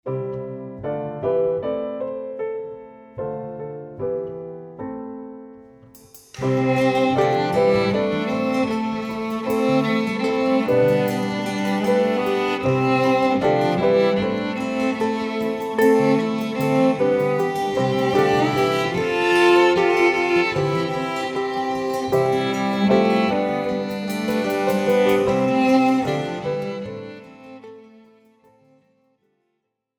Besetzung: 1-2 Altblockflöten